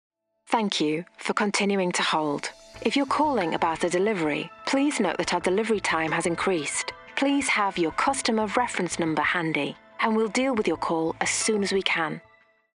Engels (Brits)
Natuurlijk, Veelzijdig, Vriendelijk
Telefonie